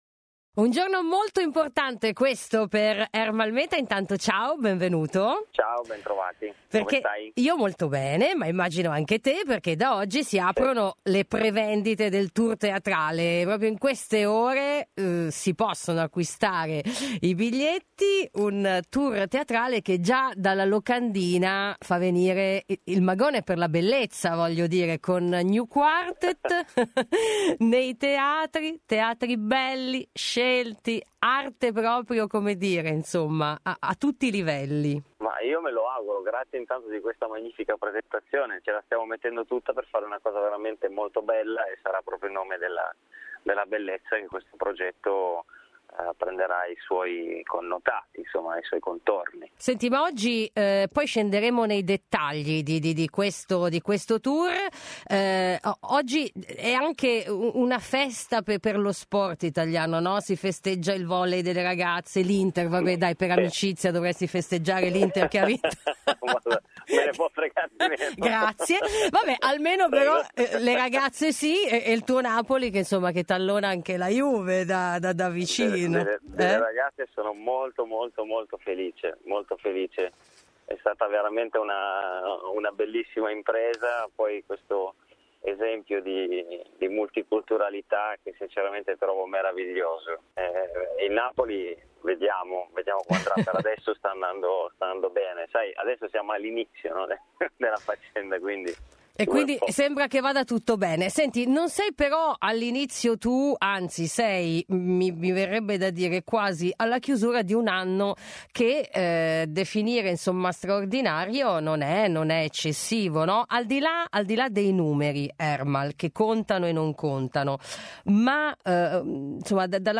Interviste | Live